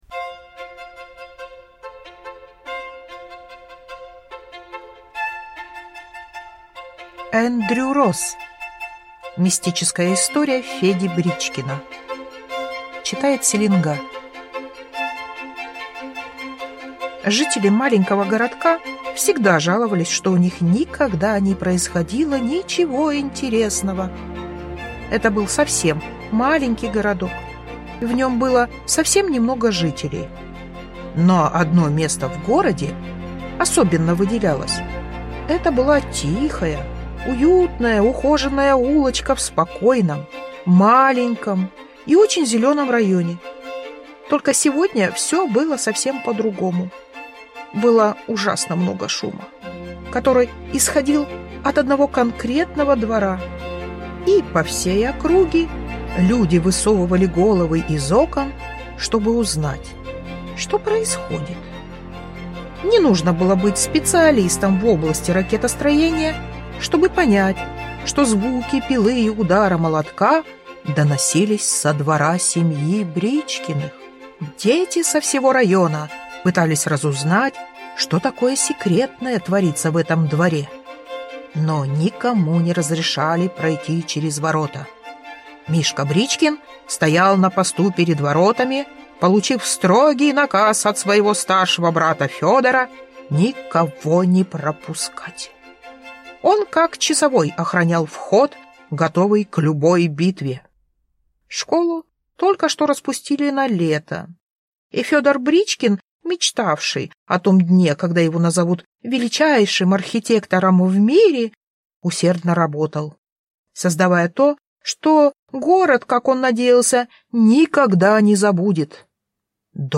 Аудиокнига Мистическая история Феди Бричкина | Библиотека аудиокниг